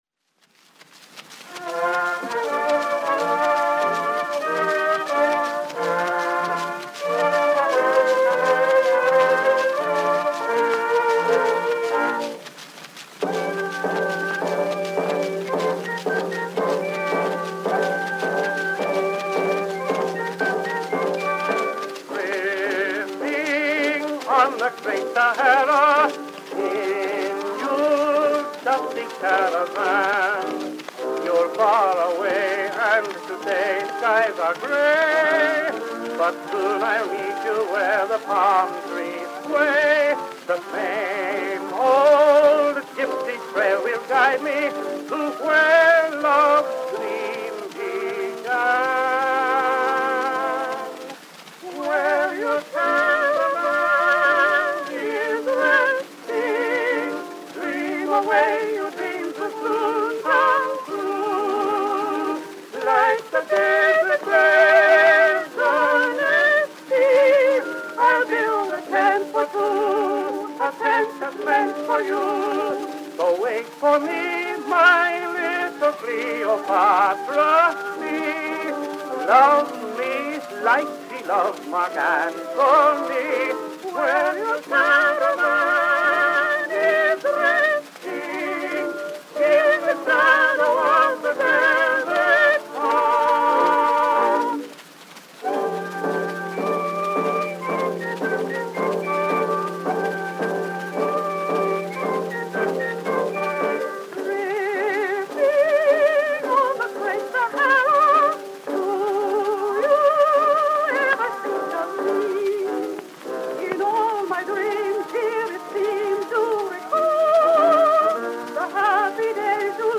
Soprano and tenor duet with orchestra accompaniment.
Popular music—1911-1920.